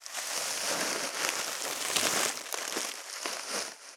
664ゴミ袋,スーパーの袋,袋,買い出しの音,ゴミ出しの音,袋を運ぶ音,
効果音